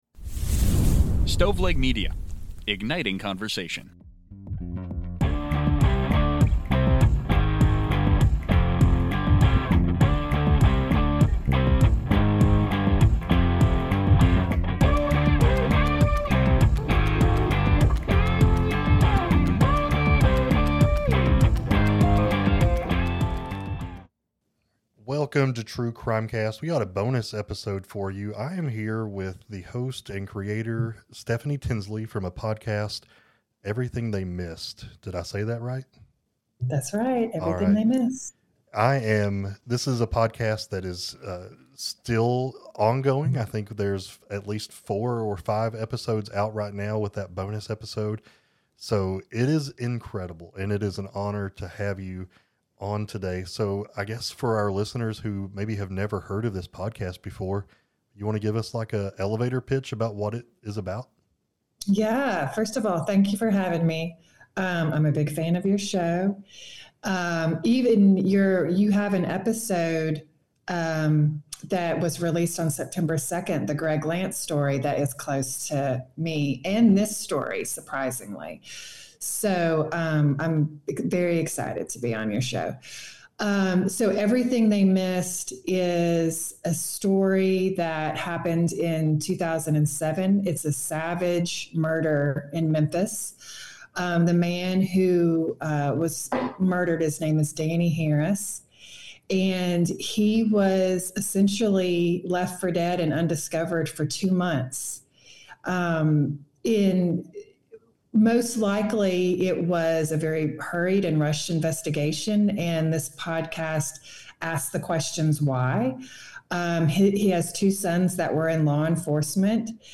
True Crime, Society & Culture, Documentary, Personal Journals